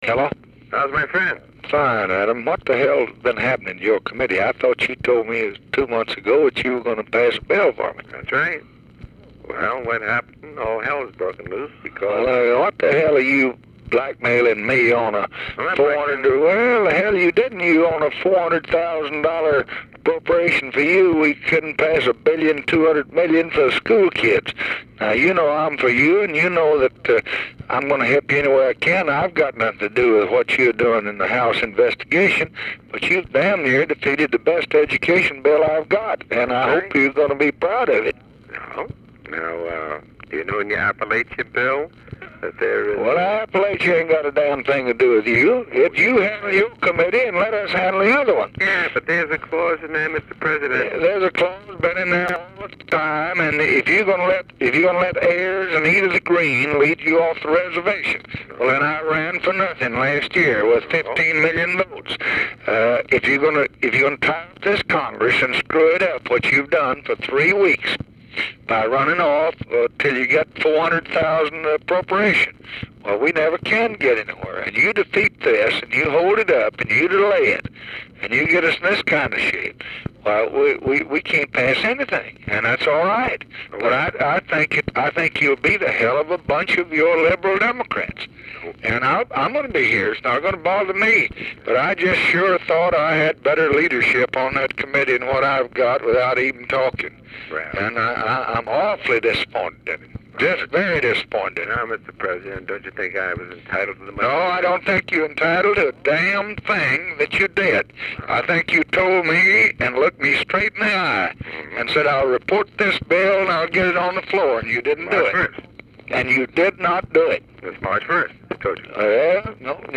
President Johnson and Adam Clayton Powell, 1 March 1965, 9.32pm, discussing the fate of the Elementary and Secondary Education Act of 1965
lbj-powell.mp3